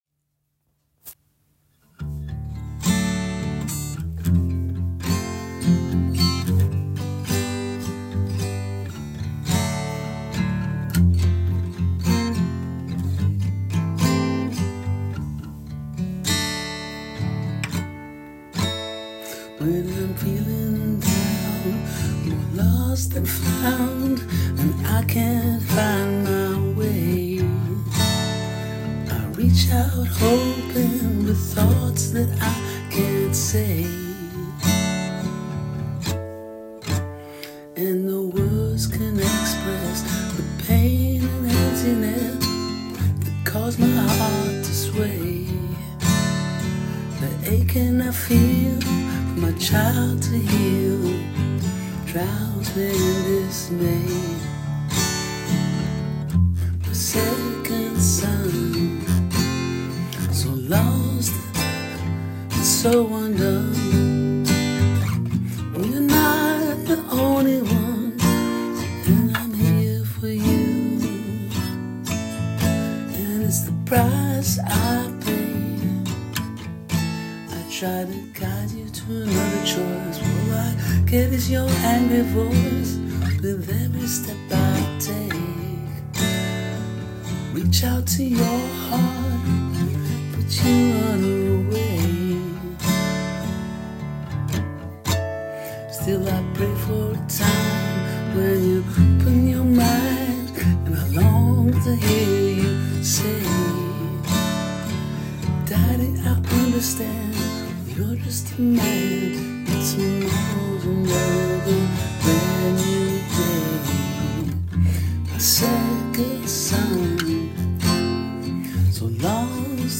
Song
The song was created by one of the Service User Evaluation participants.